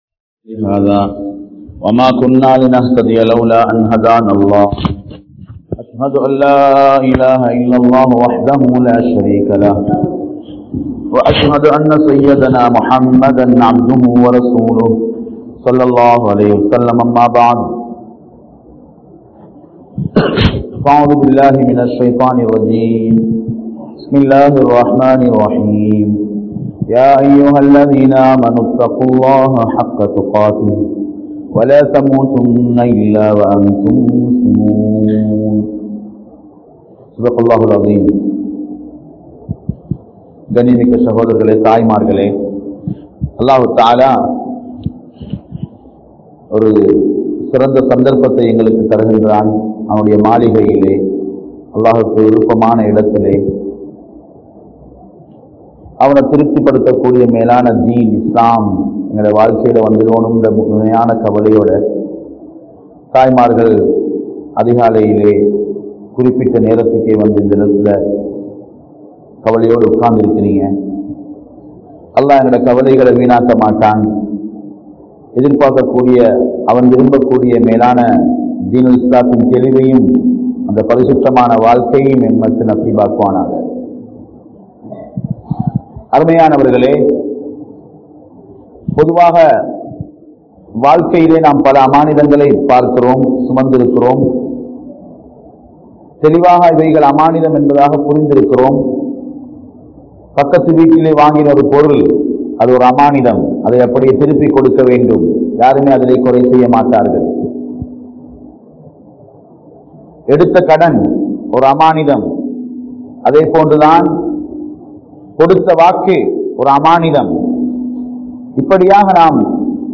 Pengal Oru Amaanithamaahum (பெண்கள் ஒரு அமானிதமாகும்) | Audio Bayans | All Ceylon Muslim Youth Community | Addalaichenai